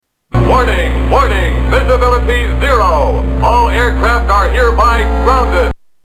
Tags: Lost in Space Robot Sounds Robot Sounds Lost in Space Robot clips Robot Lost in Space